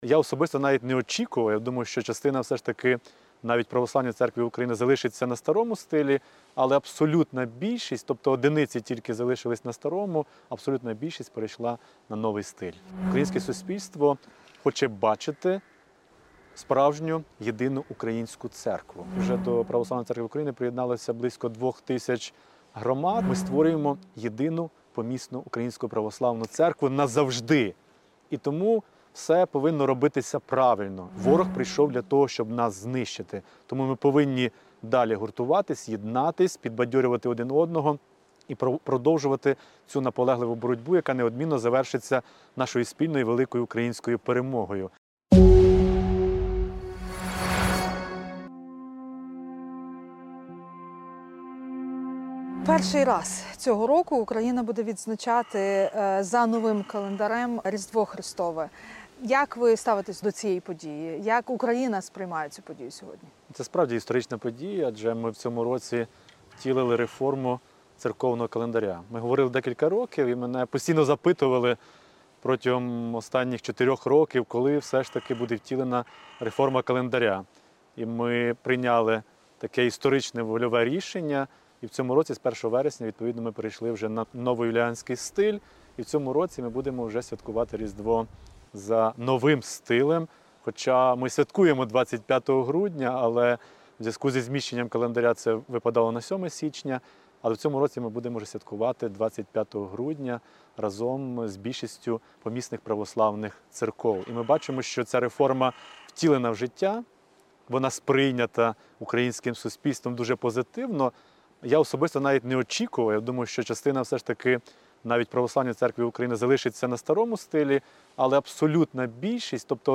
Інтерв’ю з Епіфанієм, Предстоятелем Православної церкви України. Аудіо